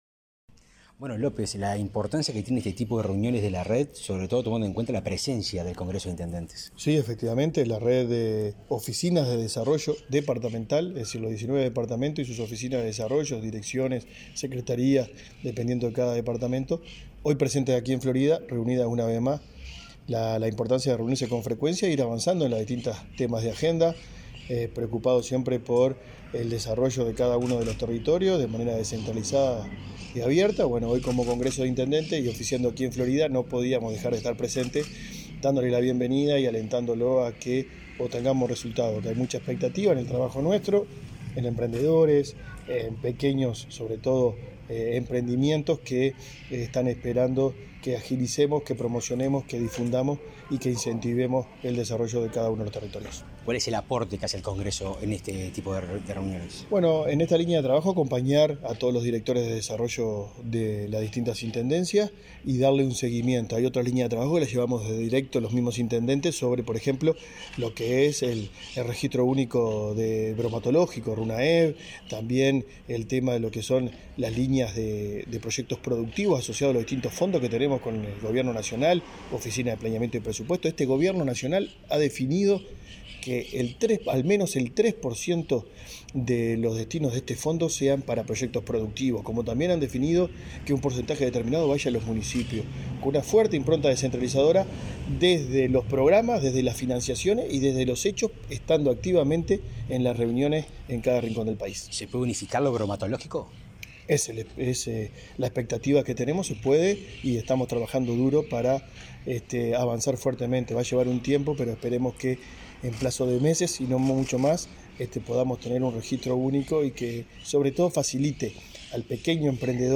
Declaraciones del presidente del Congreso de Intendentes, Guillermo López
Declaraciones del presidente del Congreso de Intendentes, Guillermo López 24/05/2022 Compartir Facebook X Copiar enlace WhatsApp LinkedIn Tras participar en la sesión de la Red de Directores de Desarrollo de los Gobiernos Departamentales, este 24 de mayo, en Florida, el presidente del Congreso de Intendentes, Guillermo López, efectuó declaraciones a Comunicación Presidencial.